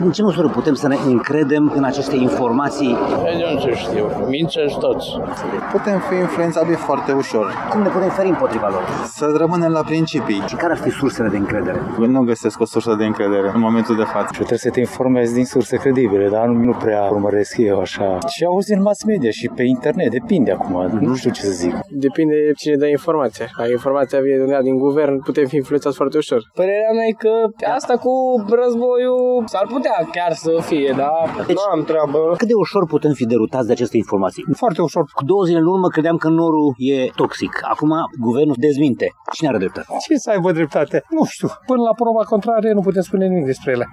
Majoritatea târgumureșenilor sunt reticenți când vine vorba de sursele de informare. Unii recunosc că nu prea urmăresc știrile, alții nu au încrederere în informații, indiferent din ce sursă vin, dar toți admit că putem fi ușor influențabili de știri, care uneori se contrazic: